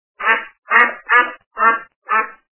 Гусь - Кря-кря Звук Звуки Гуска - Кря-кря
» Звуки » Природа животные » Гусь - Кря-кря
При прослушивании Гусь - Кря-кря качество понижено и присутствуют гудки.